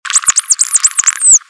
Signature Whistle Learning in Bottlenose Dolphins
dolphin.wav